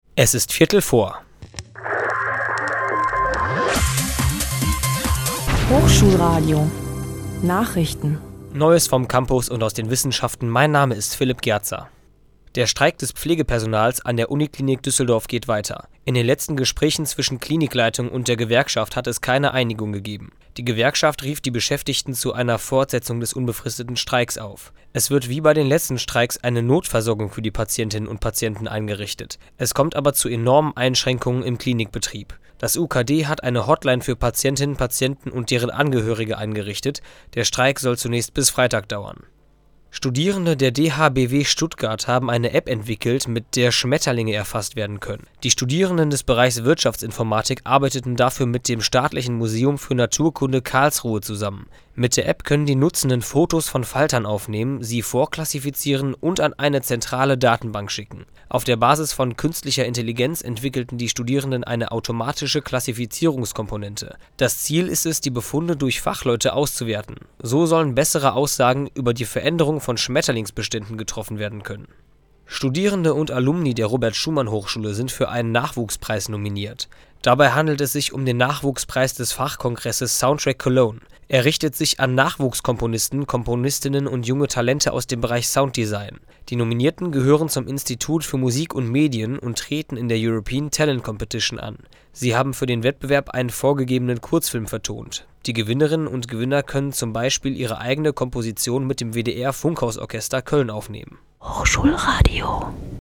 [ campusnachrichten ]